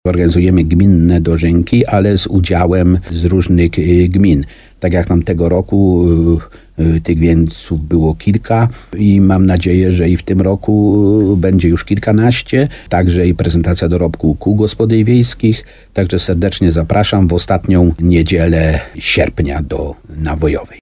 – Jest tak dlatego, że wydarzenie ma rangę powiatową – mówi wójt gminy Nawojowa, Stanisław Kiełbasa.